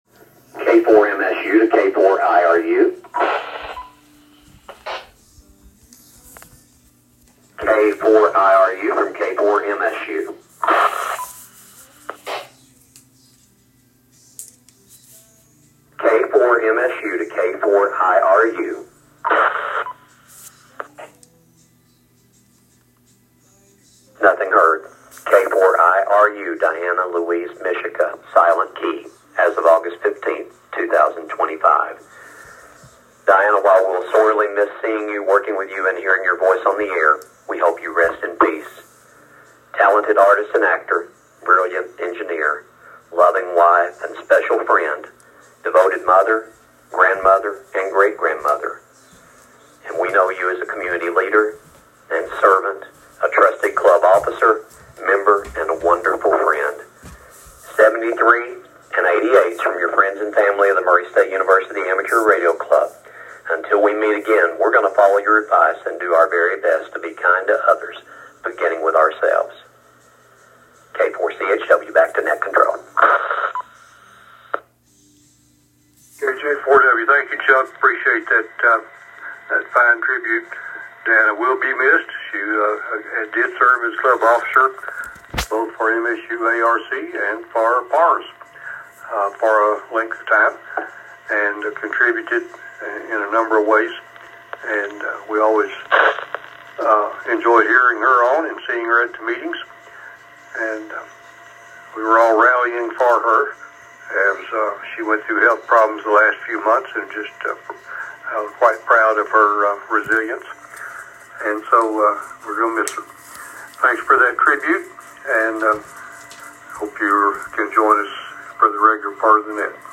SK ceremony.m4a